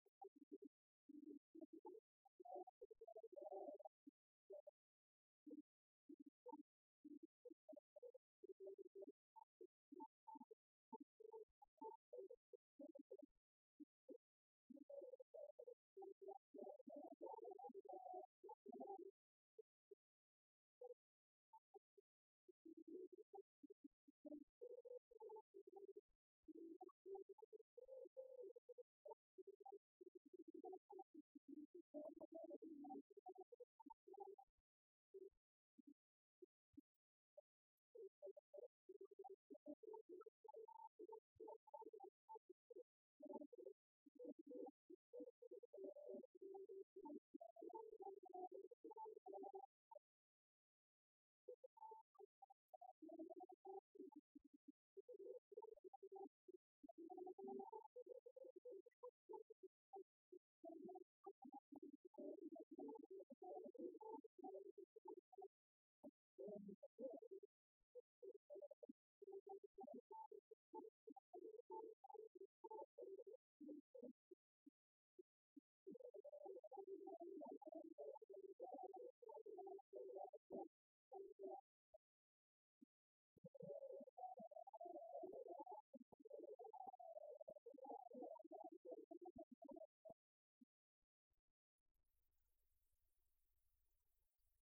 Mémoires et Patrimoines vivants - RaddO est une base de données d'archives iconographiques et sonores.
danse : valse écossaise
Pièce musicale inédite